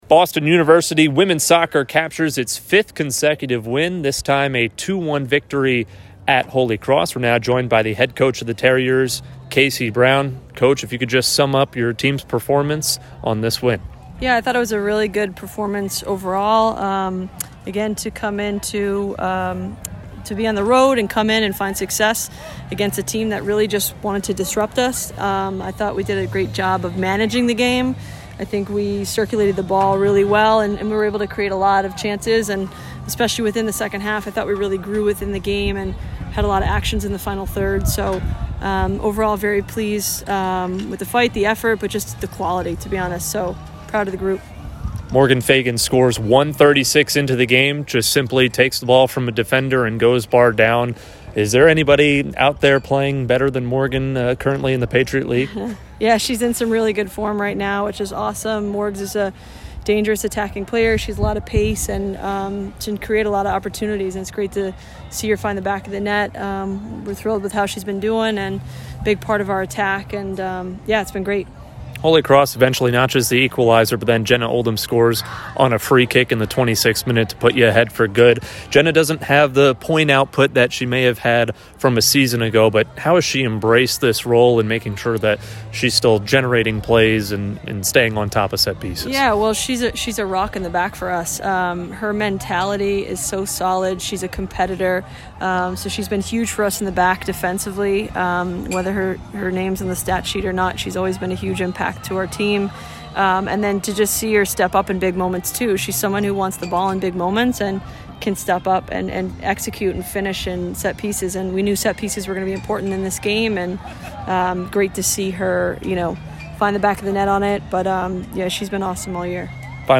Holy Cross Postgame Interviews